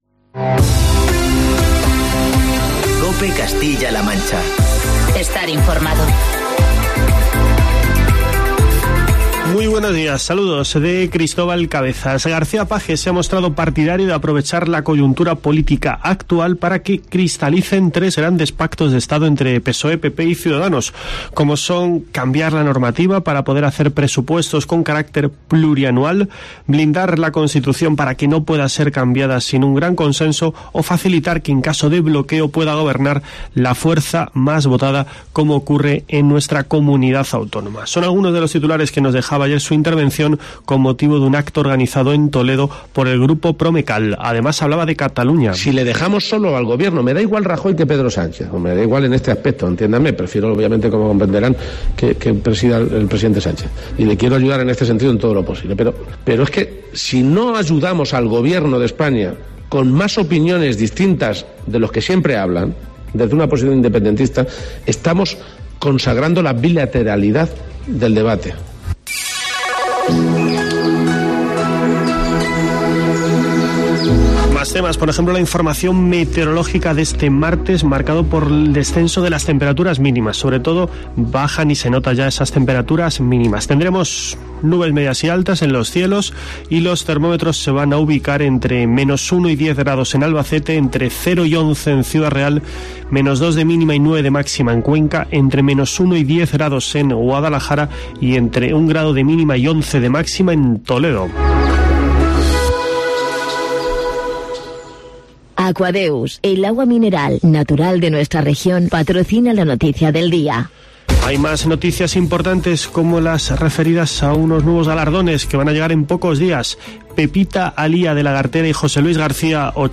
Así lo ha asegurado García-Page en un desayuno informativo organizado por el grupo Promecal en Toledo
Escucha en la parte superior de esta noticia los informativos matinales de COPE Castilla-La Mancha y COPE Toledo de este martes, 19 de noviembre de 2019.